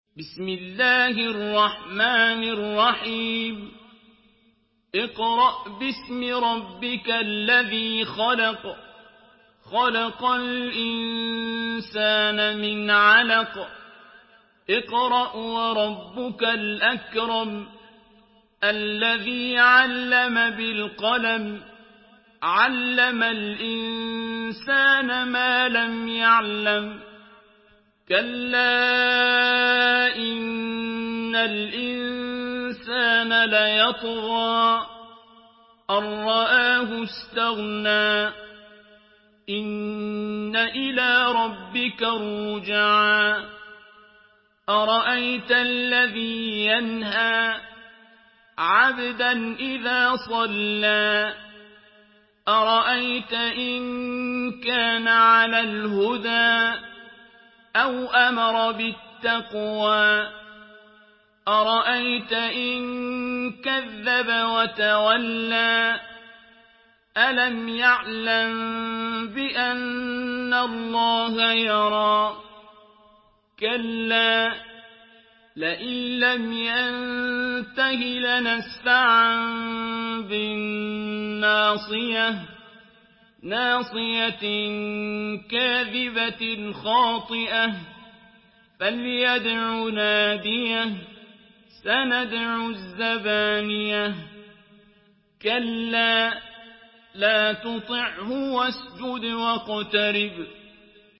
سورة العلق MP3 بصوت عبد الباسط عبد الصمد برواية حفص عن عاصم، استمع وحمّل التلاوة كاملة بصيغة MP3 عبر روابط مباشرة وسريعة على الجوال، مع إمكانية التحميل بجودات متعددة.
مرتل